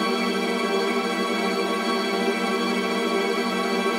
GS_TremString-Edim.wav